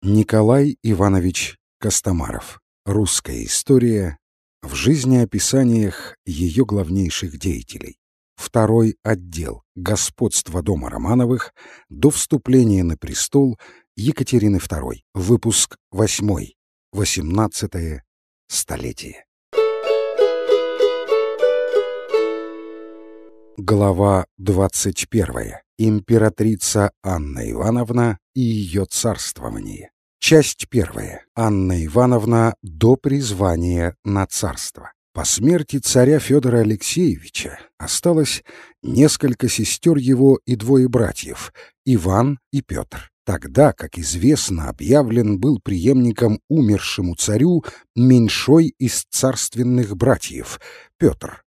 Аудиокнига Русская история в жизнеописаниях. Выпуск 8 | Библиотека аудиокниг